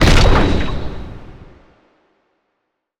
sci-fi_explosion_06.wav